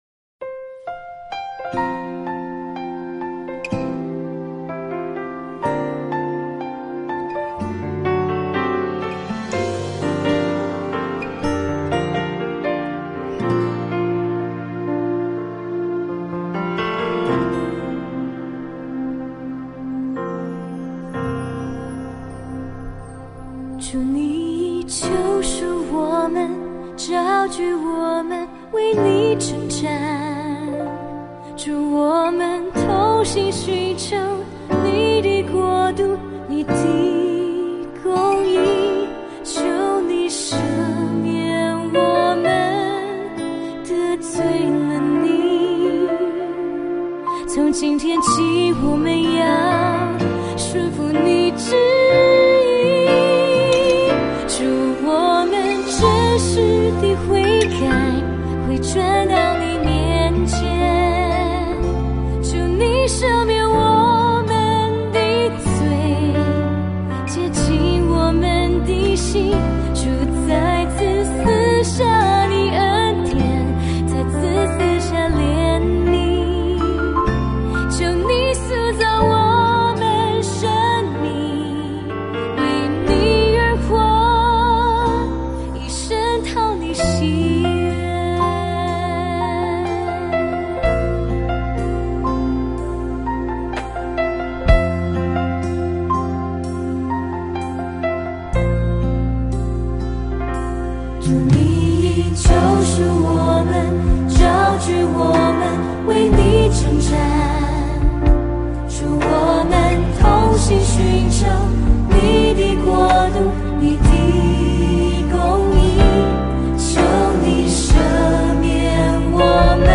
2023-03-12 敬拜诗歌 | 预告